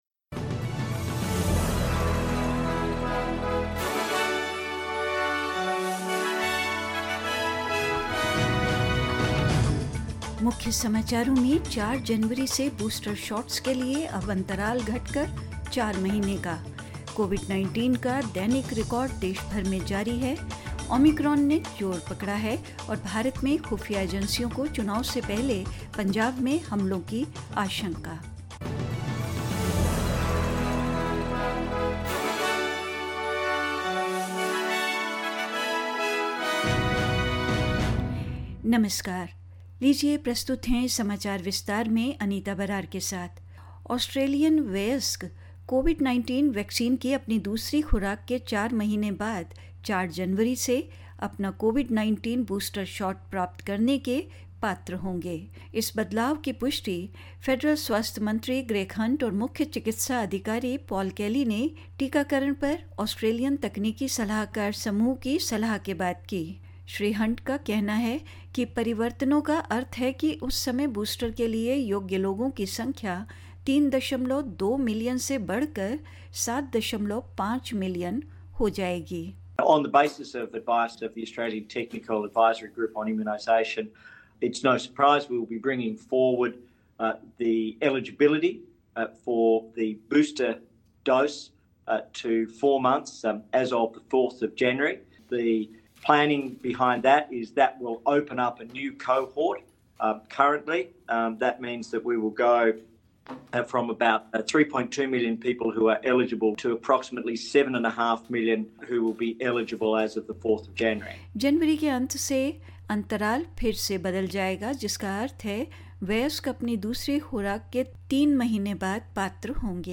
In this latest SBS Hindi news bulletin of Australia and India: Booster shot interval shortened to four months from the 4th of January; Daily COVID-19 records continue to be broken across the country as Omicron takes hold; In India, Intelligence agencies issued warning anticipating attacks in Punjab before elections and more news.